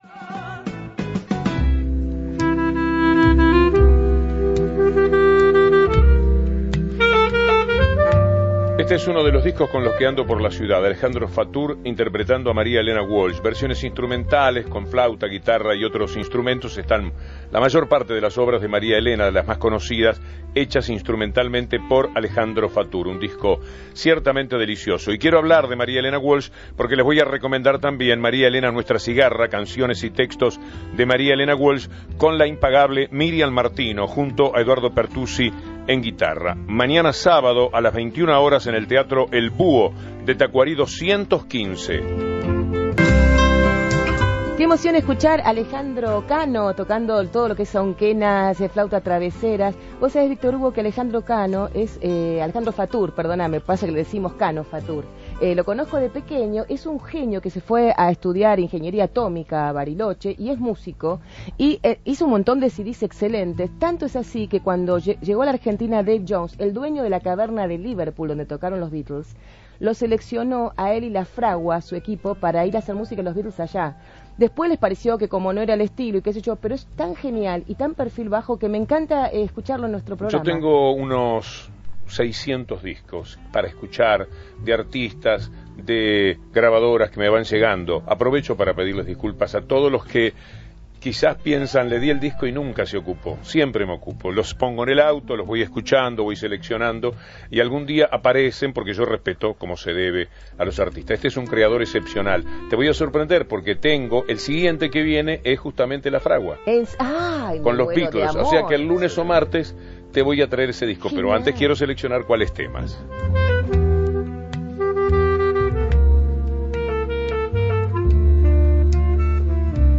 Gracias Víctor Hugo por permitirnos escuchar en tu voz tantos elogios!)